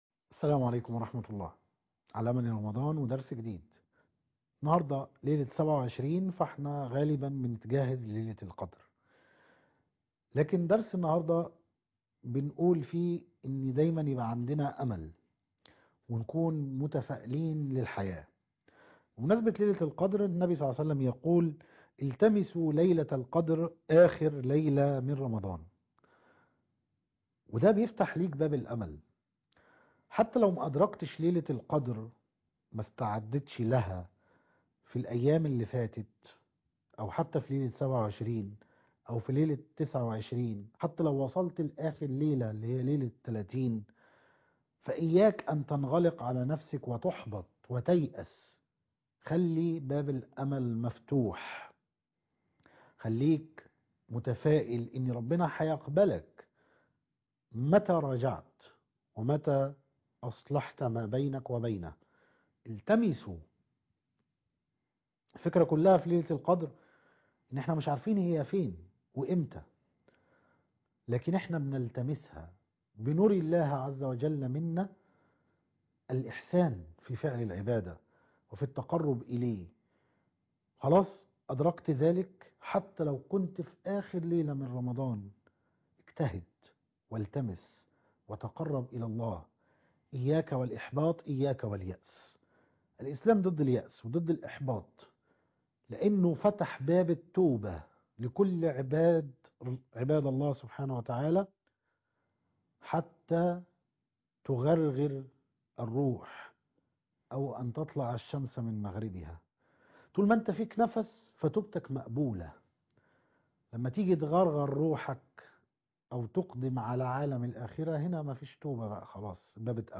المقرأة - سورة يوسف ص 246